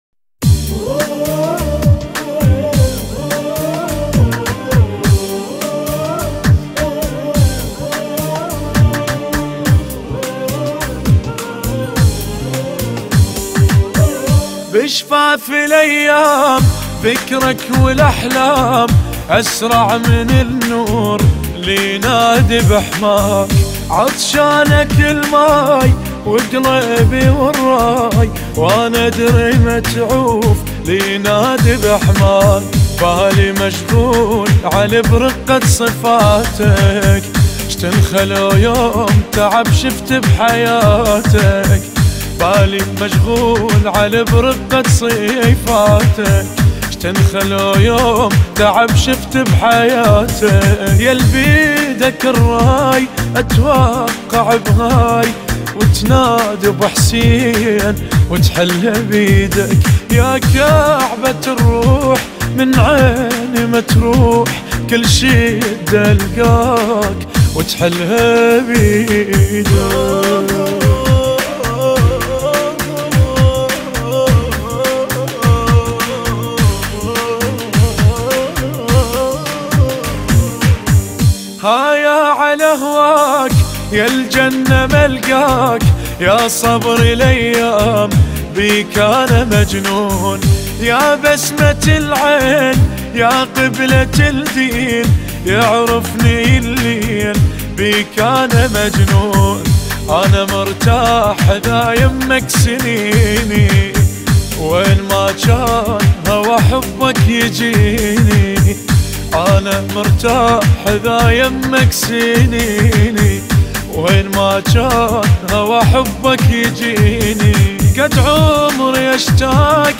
المنشد